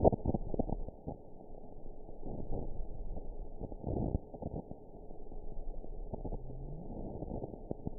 event 921576 date 12/04/24 time 21:13:34 GMT (6 months, 2 weeks ago) score 6.71 location TSS-AB05 detected by nrw target species NRW annotations +NRW Spectrogram: Frequency (kHz) vs. Time (s) audio not available .wav